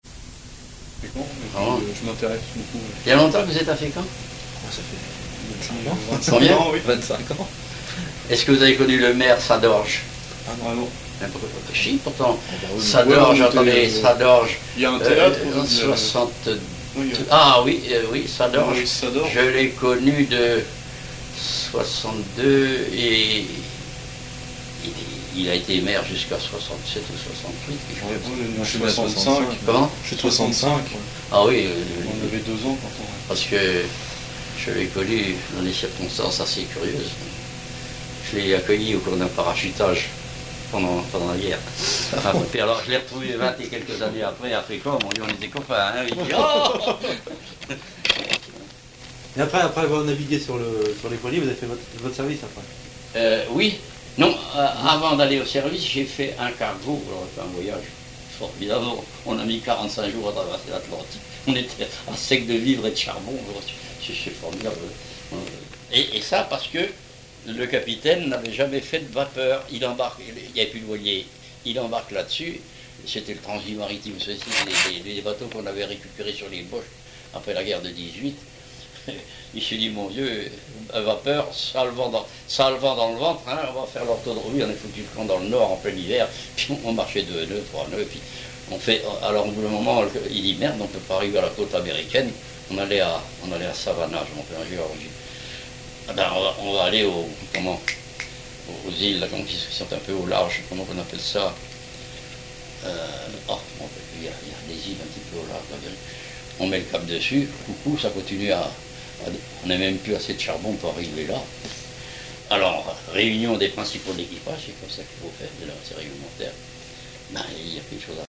Témoignage d'un cap-hornier